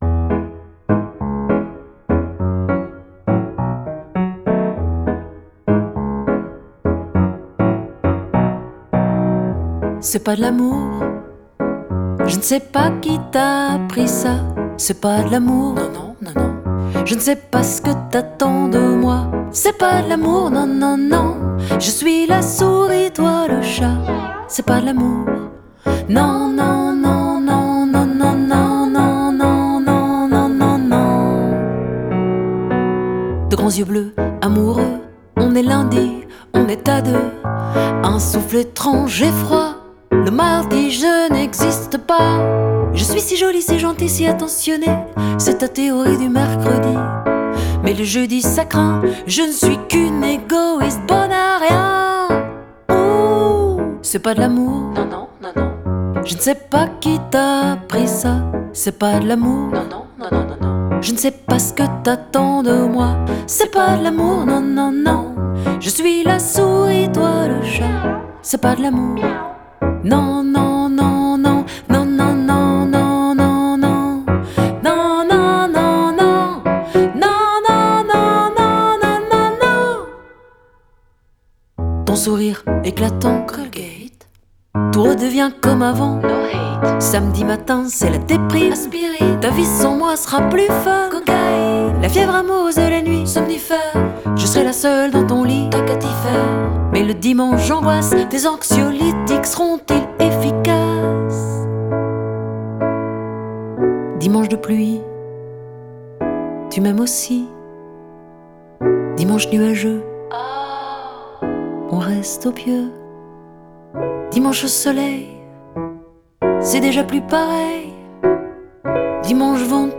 Entre chanson française, hip-hop électro et disco-punk